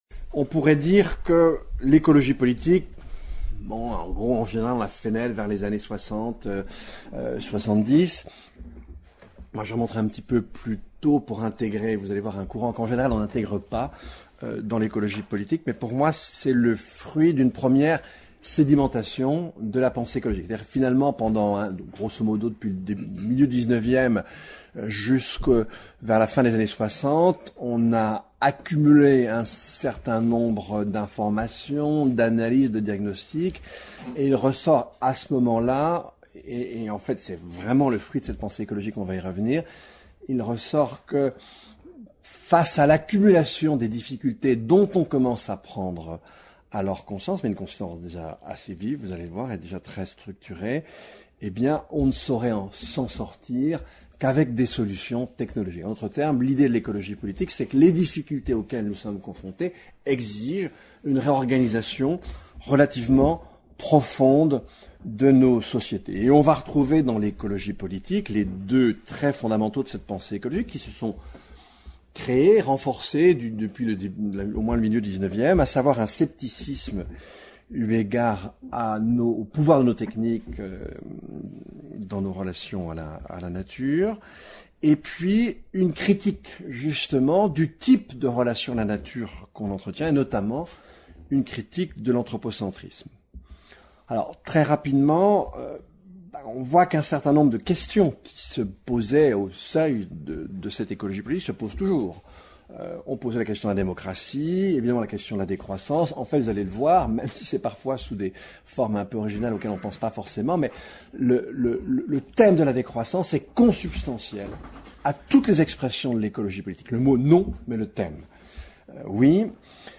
Les deux conférenciers rappellent l'évolution des idées en écologie politique depuis les années soixante-dix, en soulignant le rapport entre écologie et démocratie. Cette évolution laisse apparaître des courants différents (malthusiens, arcadiens, institutionnalistes, marxisants, apocalyptiques, anti-démocratiques).